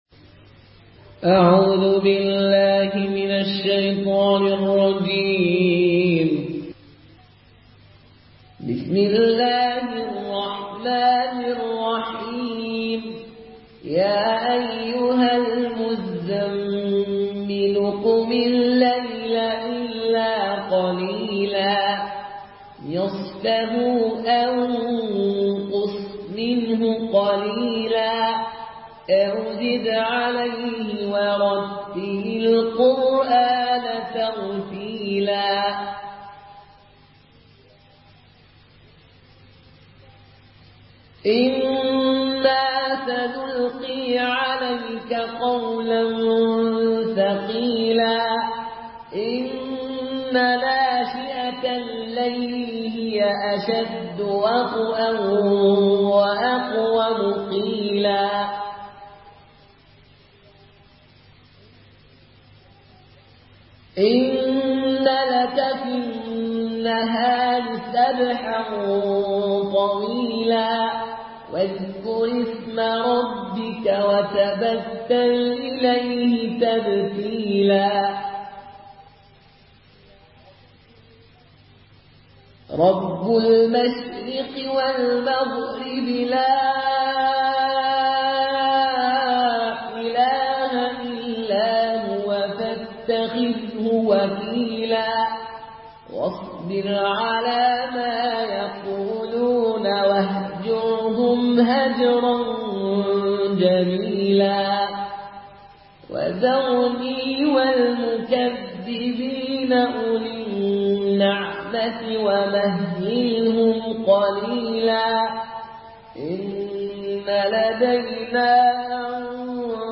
Une récitation touchante et belle des versets coraniques par la narration Qaloon An Nafi.
Murattal